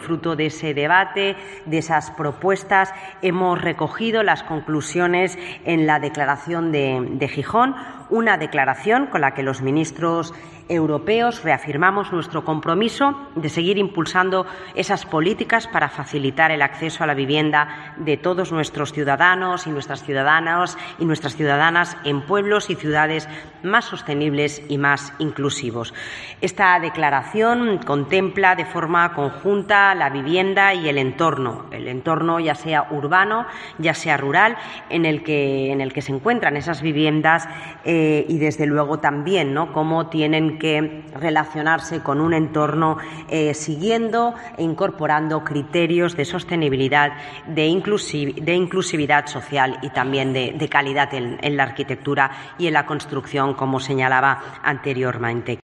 Raquel Sánchez explica el contenido de la "Declaración de Gijón"